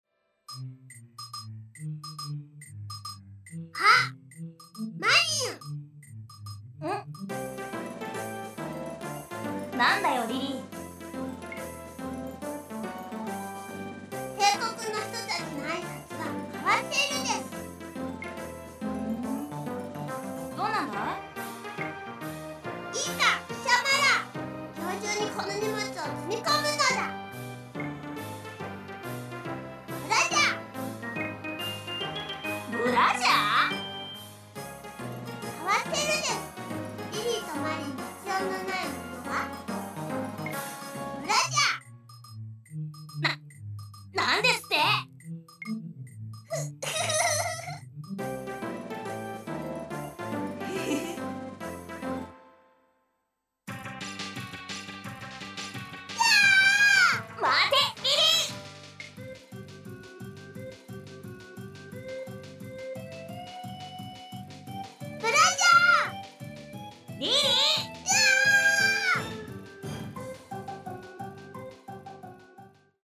音量は意図的に小さめにしてあります。